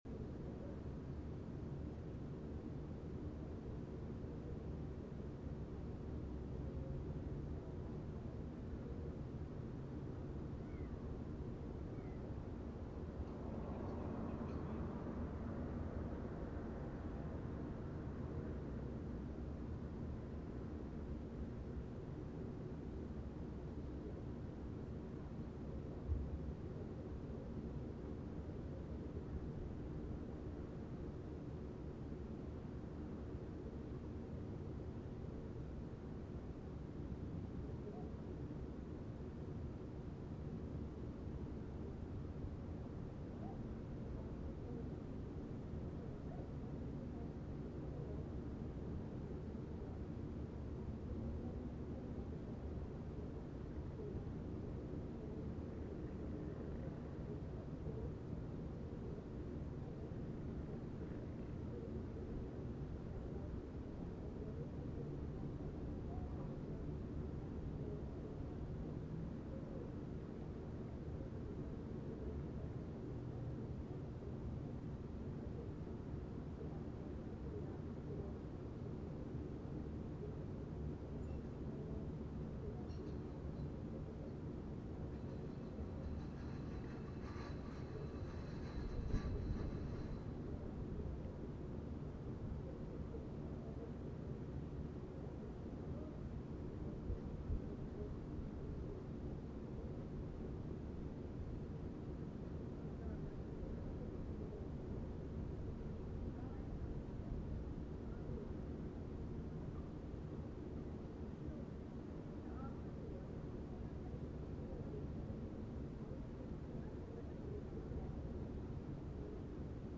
Just 3 minutes of calm street sounds and clouds drifting by.